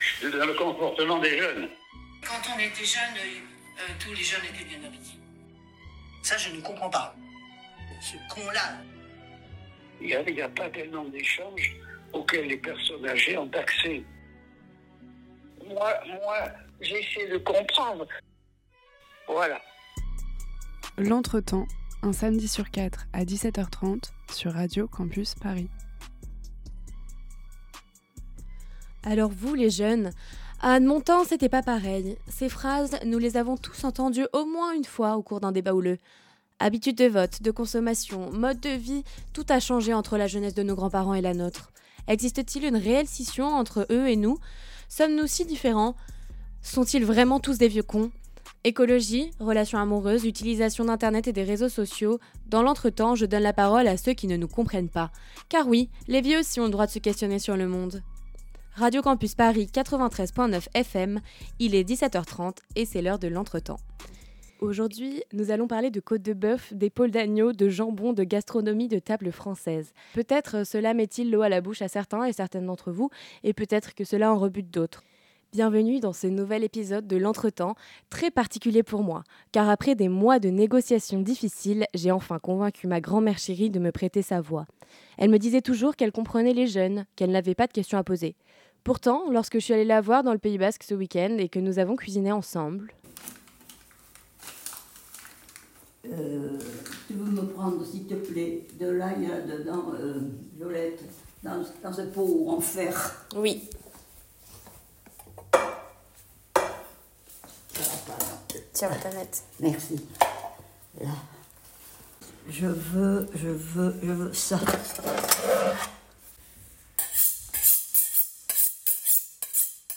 Partager Type Entretien Société samedi 14 décembre 2024 Lire Pause Télécharger Episode très spécial